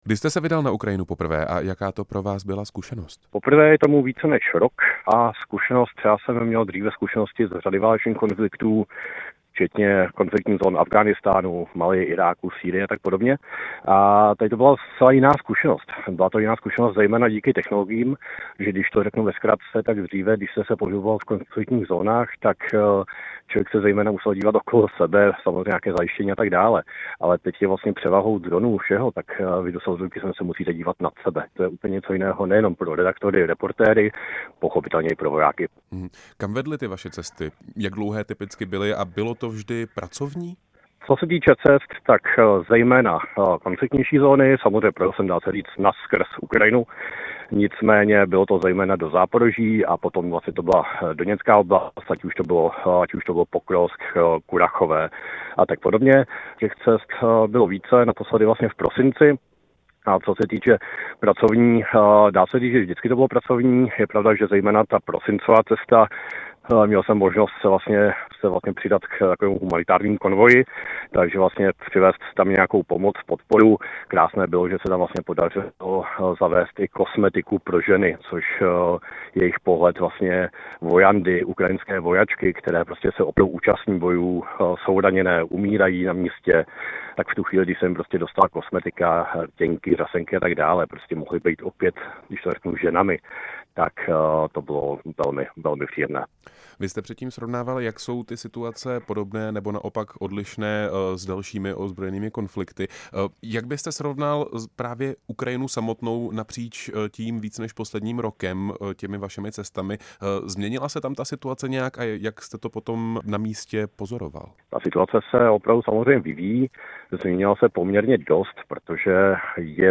Jak probíhá redaktorská práce přímo z dějiště válečného konfliktu a jaká to byla zkušenost? O jeho cestách jsme si s ním povídali přímo ve vysílání Radia Prostor.